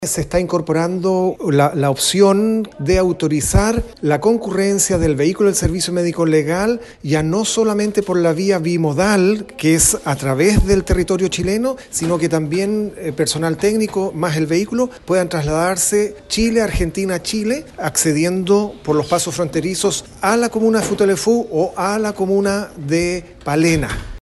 Alex Meeder, Seremi de Justicia en la Región de Los Lagos, respondió que se están realizando acciones para beneficiar a los habitantes de la provincia de Palena.